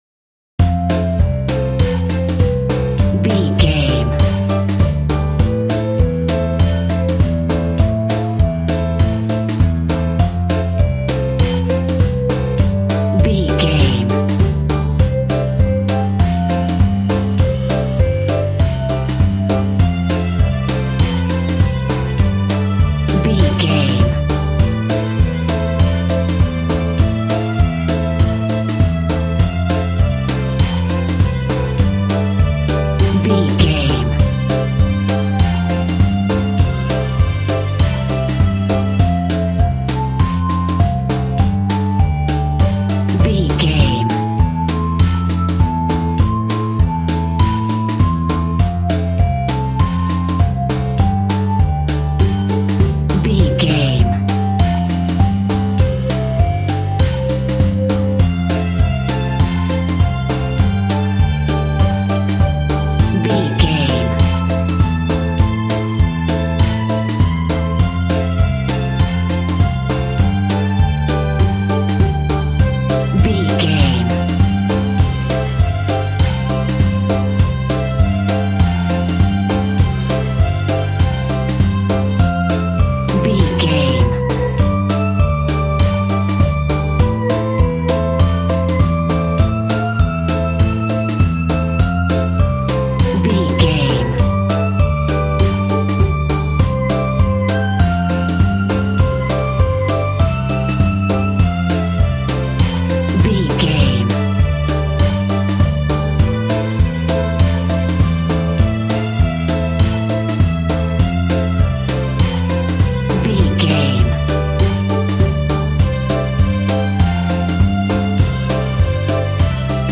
Ionian/Major
childrens music
instrumentals
childlike
cute
happy
kids piano